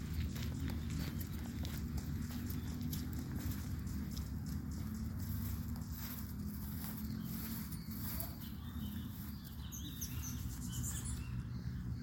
Picaflor Vientre Blanco (Elliotomyia chionogaster)
Nombre en inglés: White-bellied Hummingbird
Provincia / Departamento: Tucumán
Localidad o área protegida: San Miguel, capital
Condición: Silvestre
Certeza: Vocalización Grabada